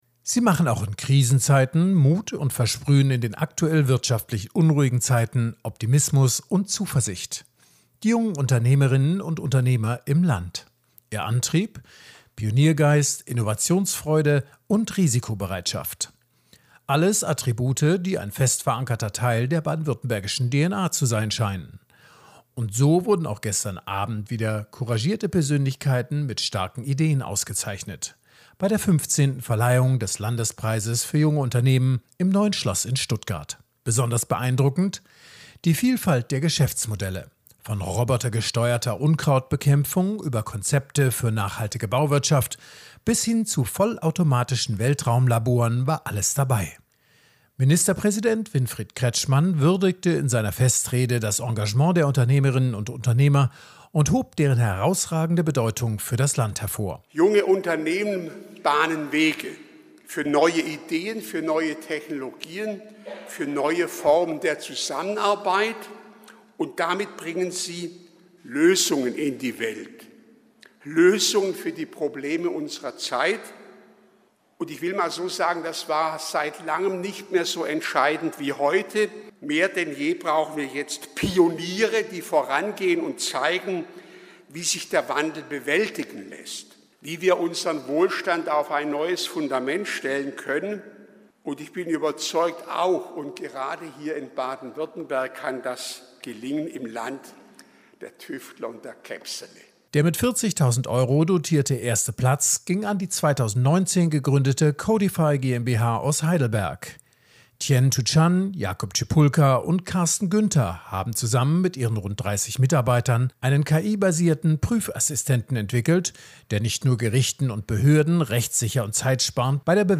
Sendefähige Interviews, Statements und O-Töne zu aktuellen Themen
• Radio-Feature Preisverleihung des Landespreises für junge Unternehmen am 26.11.2024
Ministerpräsident Winfried Kretschmann würdigte in seiner Festrede das Engagement der Unternehmerinnen und Unternehmer und hob deren herausragende Bedeutung für das Land hervor.